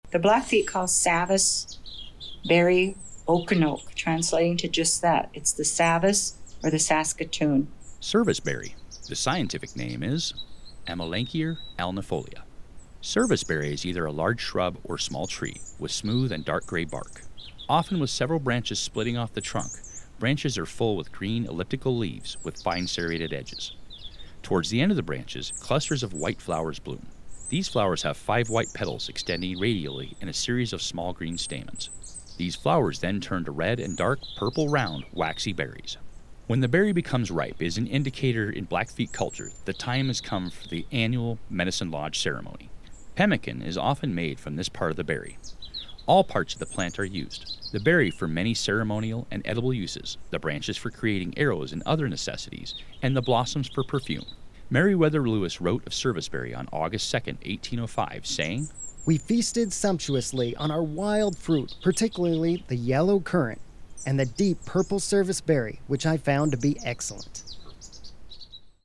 Narration: